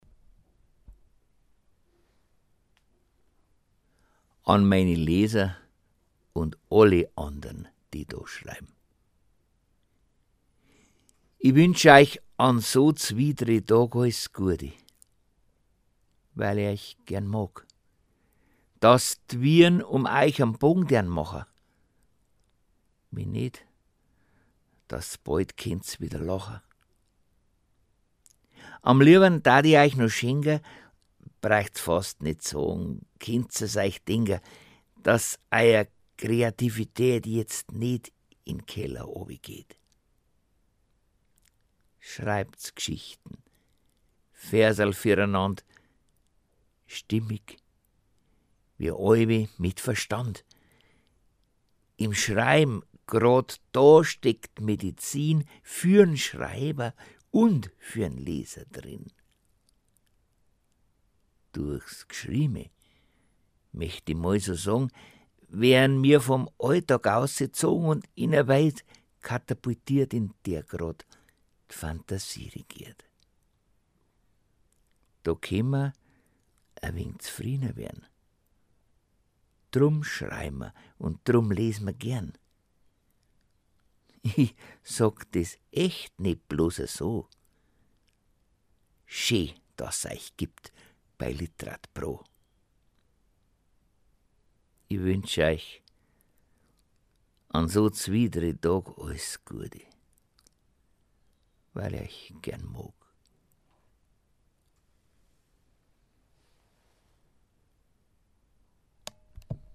ste-083_an_meine_leser.mundart.mp3